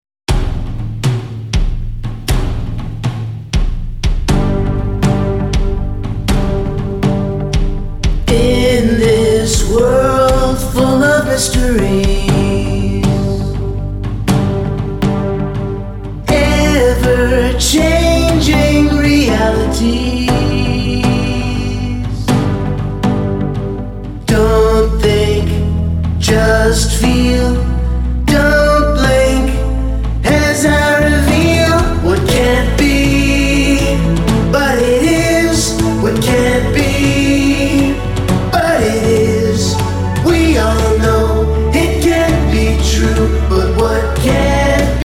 kid funk, blues, pop, rock, country, anthem and ballad